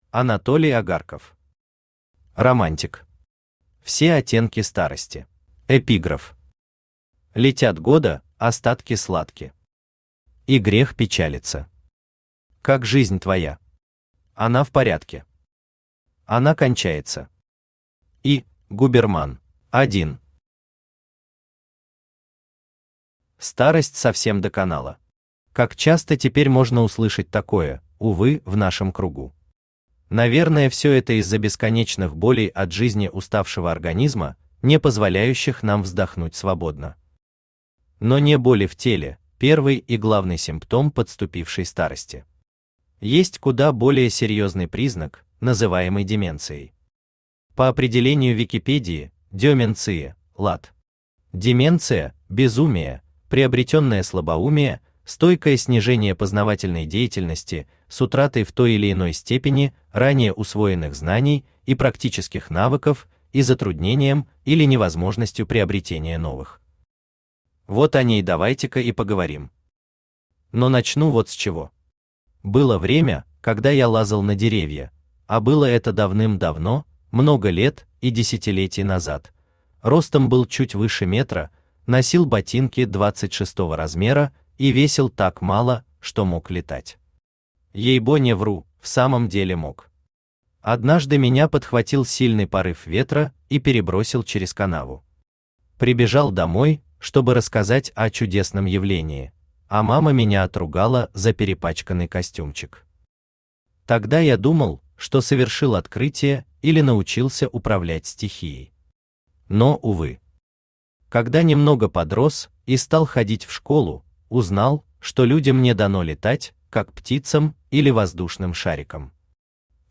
Аудиокнига Романтик. Все оттенки старости | Библиотека аудиокниг
Все оттенки старости Автор Анатолий Агарков Читает аудиокнигу Авточтец ЛитРес.